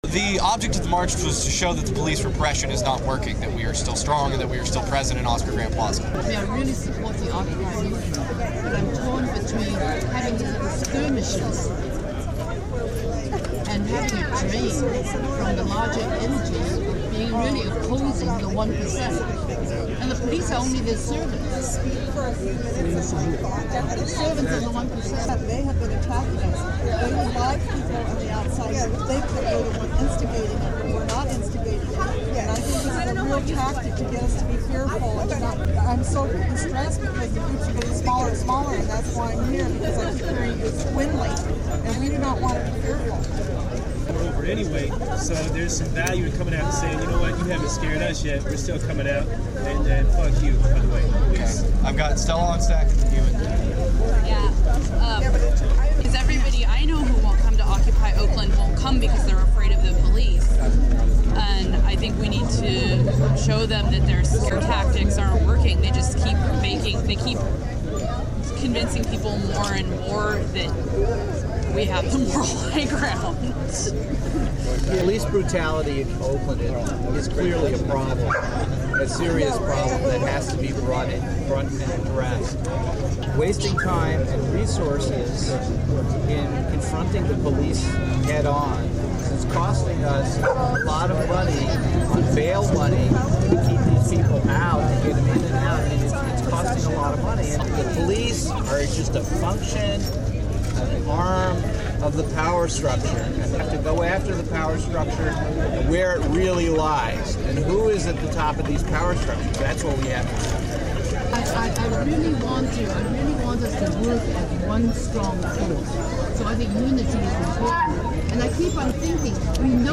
The diversity of organizing efforts out of Occupy Oakland in the new year is captured in this report and 12 minute audio of Sunday, January 8, 2012's General Assembly.
occupy_oakland_ga_voices_1.8.12_short.mp3